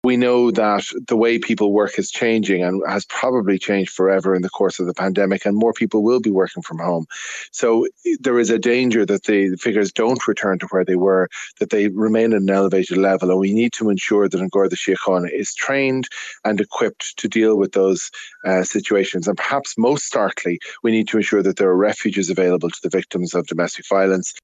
Criminal barrister and Fine Gael senator, Barry Ward, says the numbers may not drop when Covid lockdowns end: